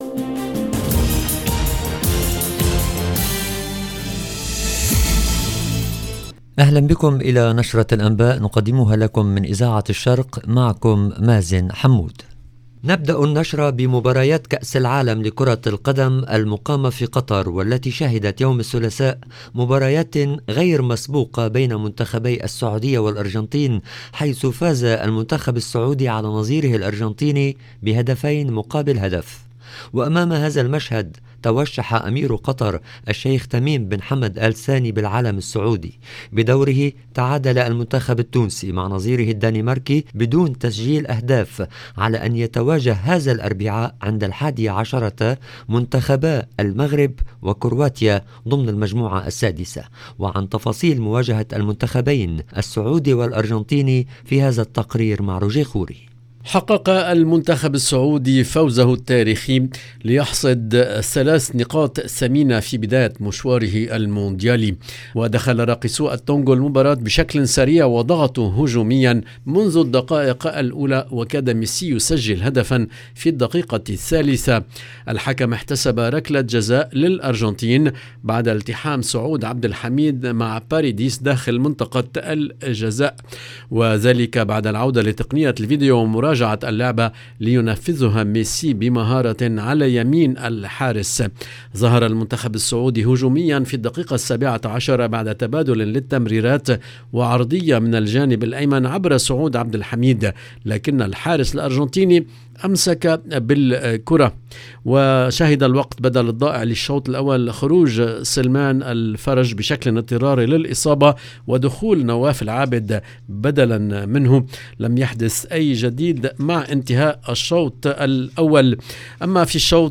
LE JOURNAL DU SOIR EN LANGUE ARABE DU 22/11/22
ونستضيف السفير اللبناني في فرنسا رامي عدوان